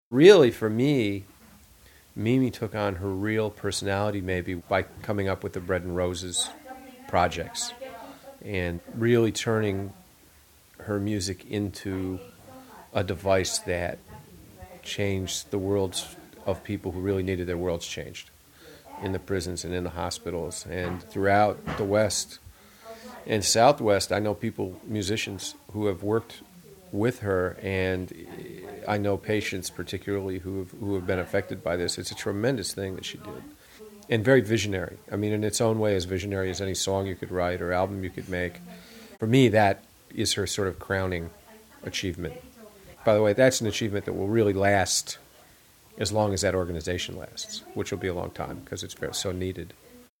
Dave Marsh - Commentary by Dave Marsh in 2001. (:50)